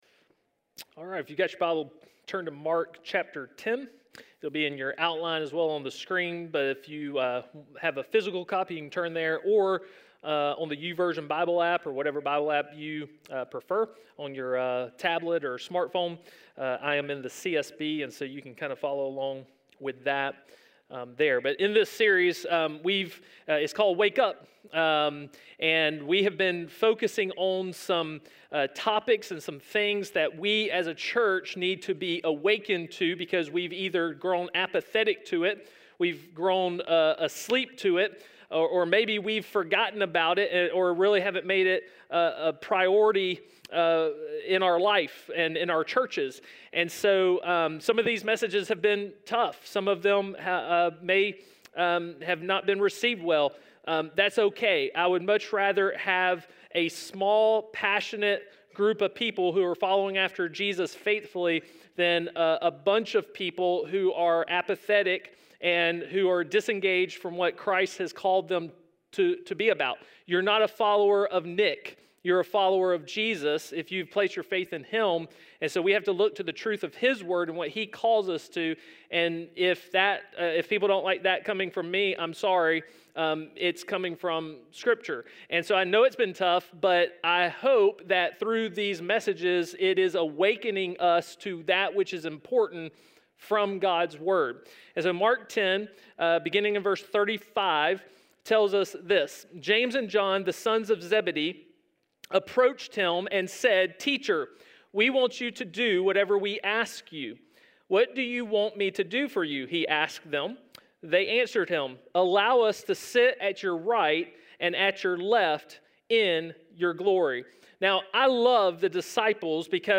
A message from the series "Wake Up!."